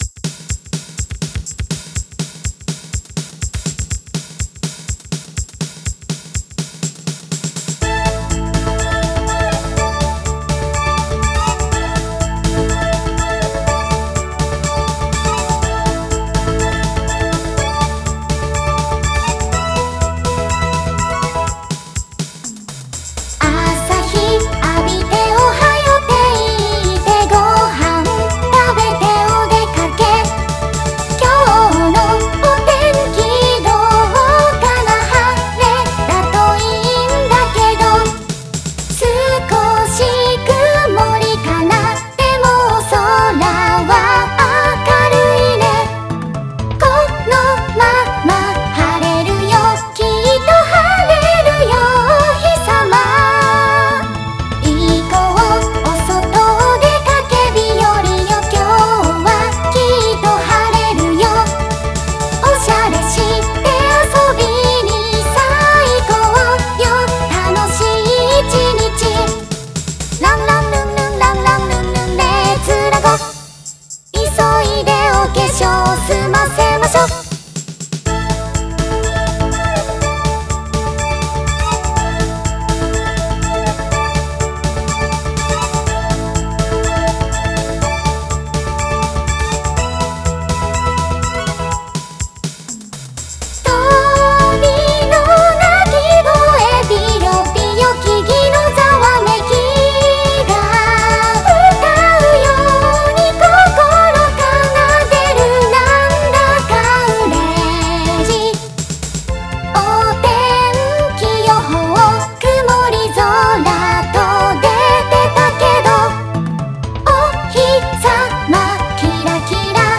近年の合成音声はクオリティが高く、まるで人間のように自然な声で歌います。
Vocal Synthesizer
可愛くポップな感じの歌です。